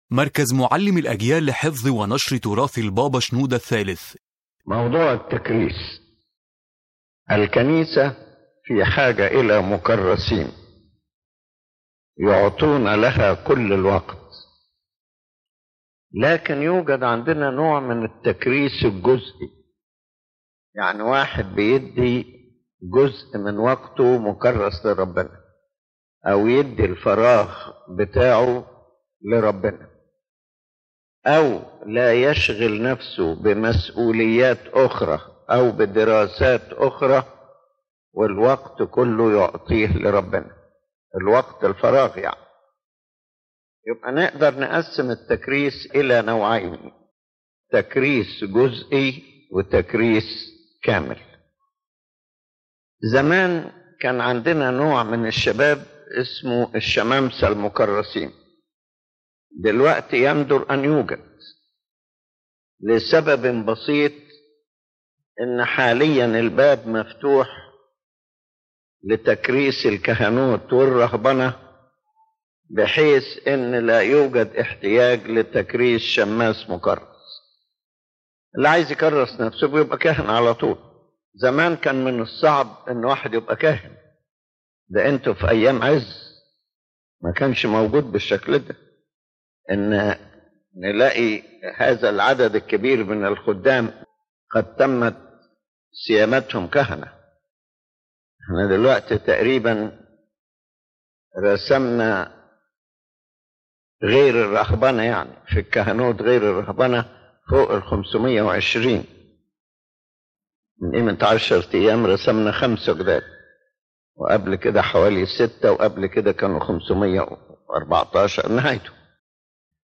This lecture addresses the concept of consecration in the Coptic Orthodox Church, clarifying its spiritual and organizational dimensions, and placing consecration in its proper framework as a calling to service and self-offering, not as a means for personal gain or ecclesiastical positions.